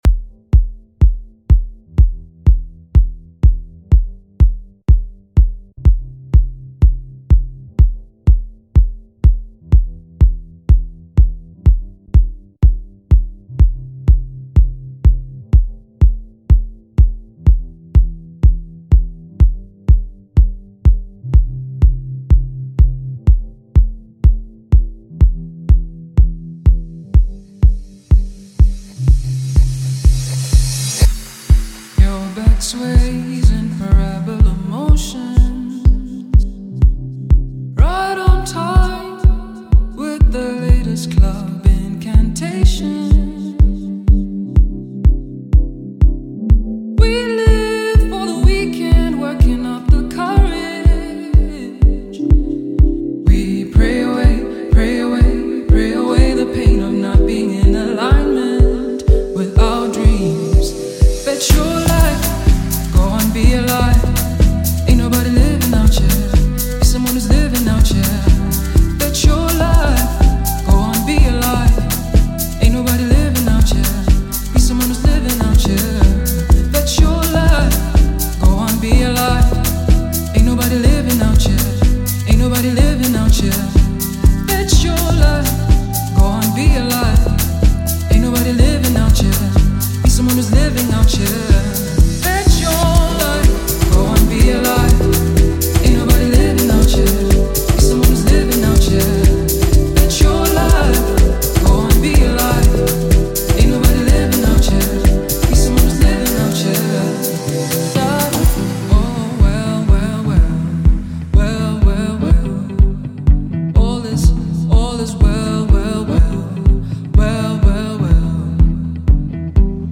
South African vocal powerhouse